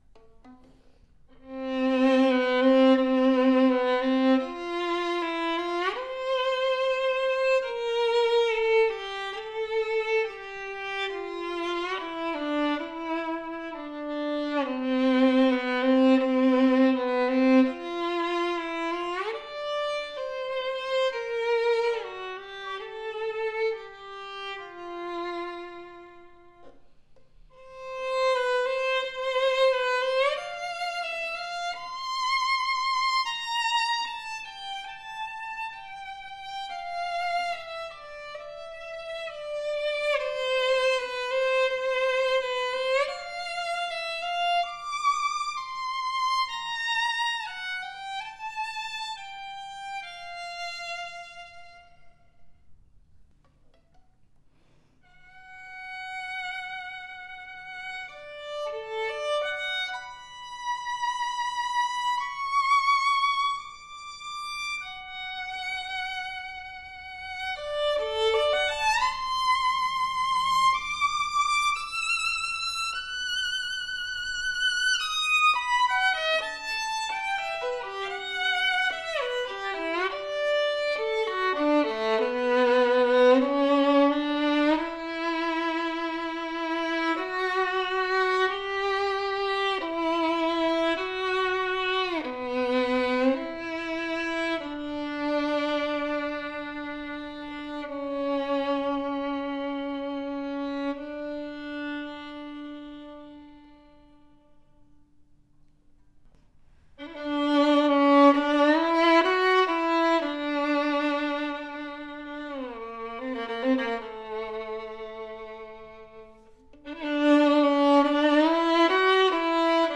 • Classic Italian antique golden brown varnish, tone oriented.
A RARE fine sounding violin at this price range, special edition made after the Gagliano pattern, that delivers a surprising WARM SWEET and PROJECTIVE tone, more tone than you would expect at this price range!! Exquisite antique reddish brown varnish, full and extra higher arching creates a powerful tone with deep and bold projection. A vibrant, mellow sounding violin that built with seriousness, our most affordable instrument that represents super value for violin players.